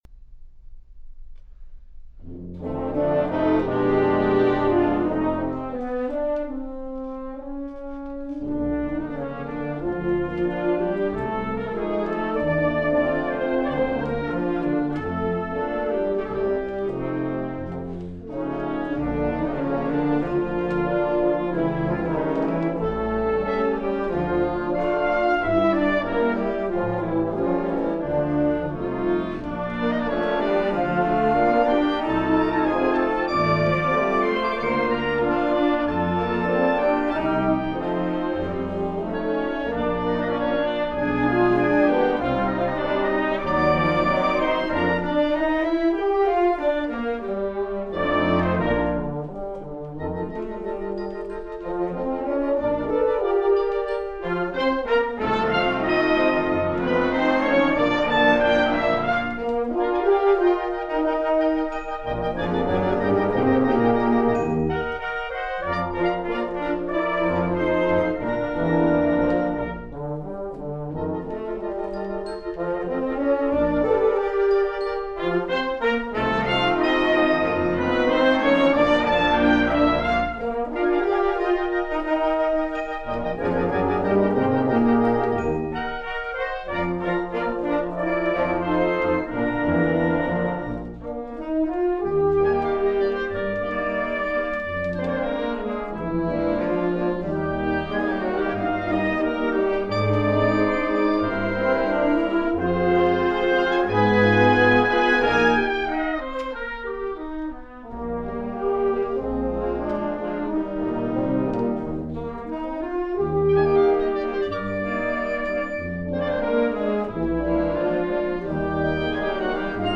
Lola's Dream, Mexican Serenade, by Theodore Tobani
using a pair of Oktava MK-12 omnidirectional
microphones, with an Edirol UA-25 A/D resulting in 24 bit 96KHz master